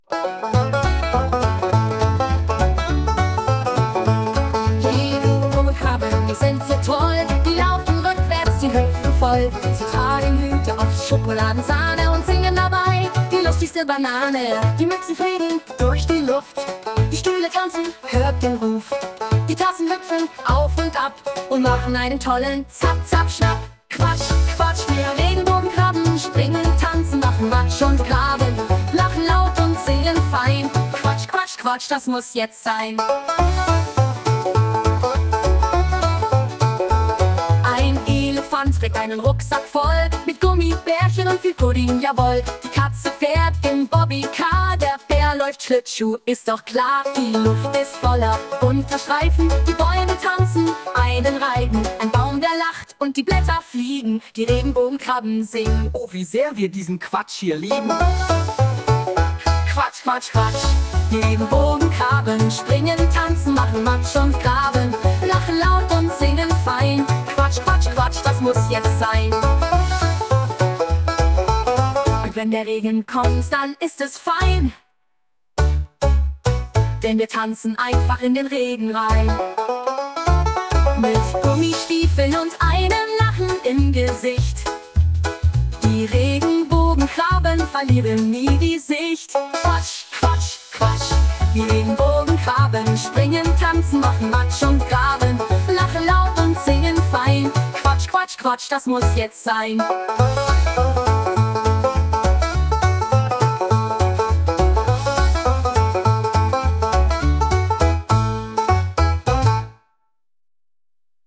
Die KI liefert zu eigenen oder ebenfalls mit KI generierten Texten komplette Lieder mit realistischen Gesangsstimmen, Melodien und Instrumenten, die dann individuell angepasst werden können.